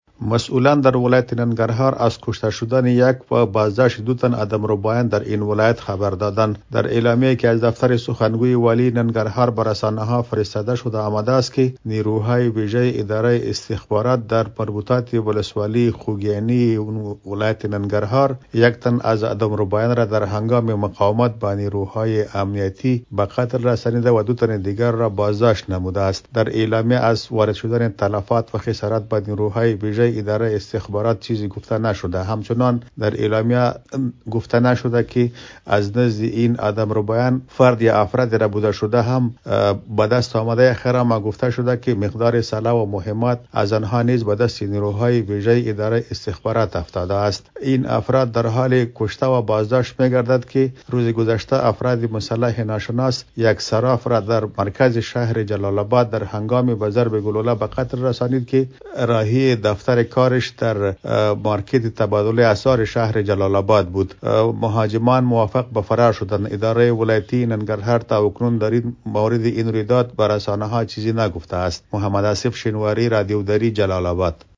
خبرنگار رادیودری